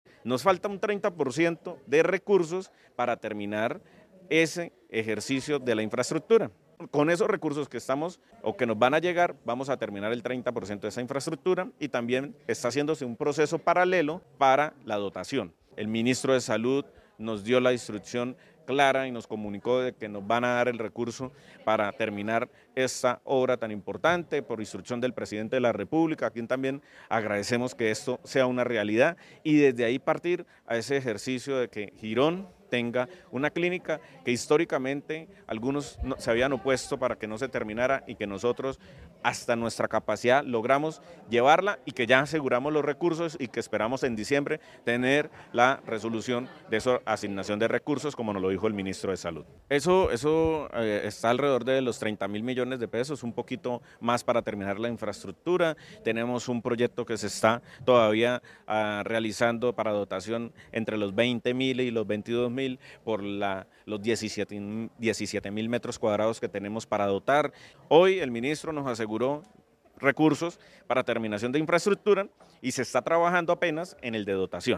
Campo Elías Ramírez, alcalde de Girón